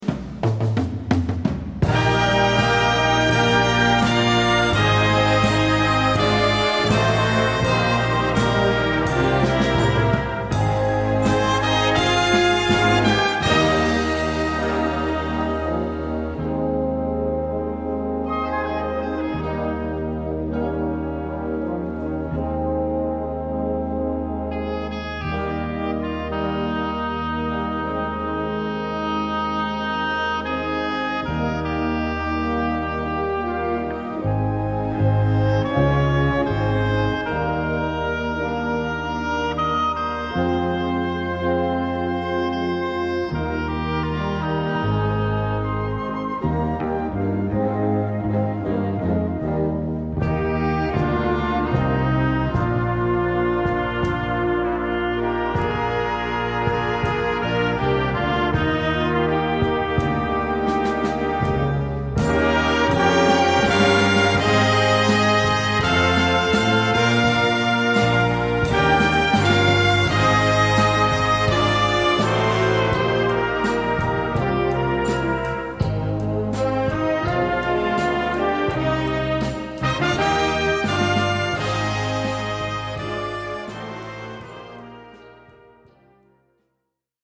Gattung: Selection
Besetzung: Blasorchester